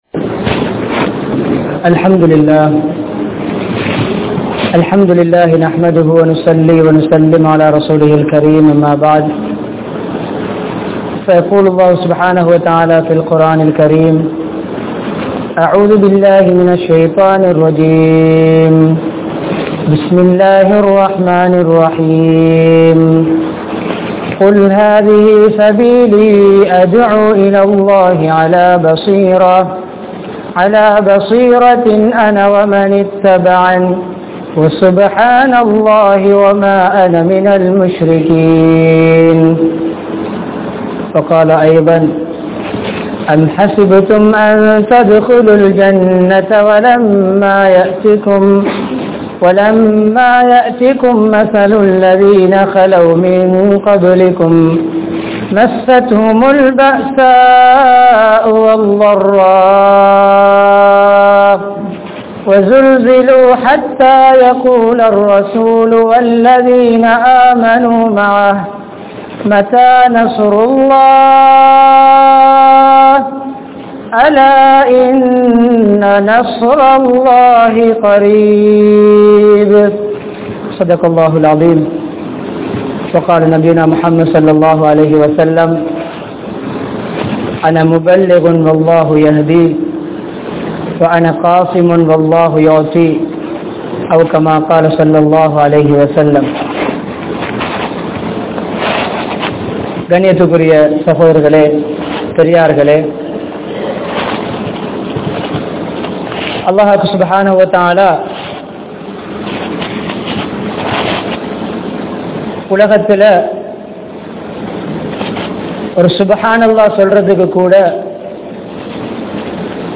Dhaee In Andhasthu (இஸ்லாமிய அழைப்பாளனின் அந்தஸ்த்து) | Audio Bayans | All Ceylon Muslim Youth Community | Addalaichenai
Gampaha, Pugoda, Kumarimulla Jumua Masjidh